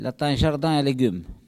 Elle provient de Saint-Urbain.
Locution ( parler, expression, langue,... )